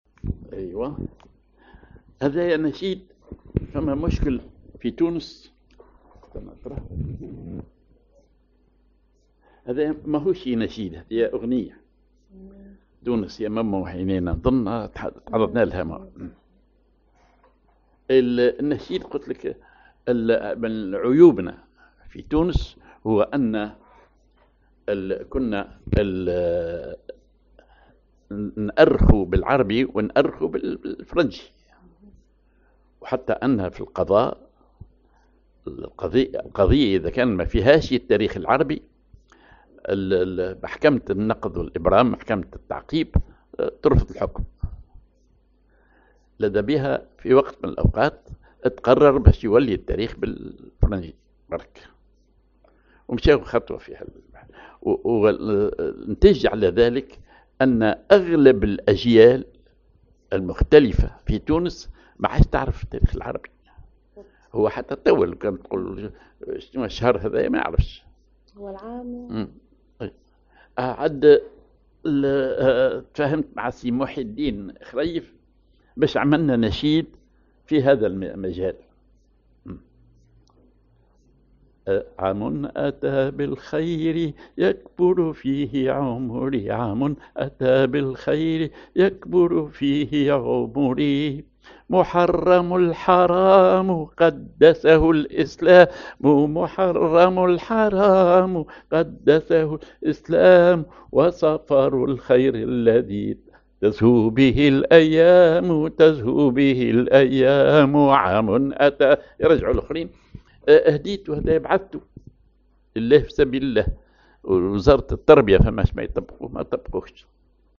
ar مزموم
ar ختم
نشيد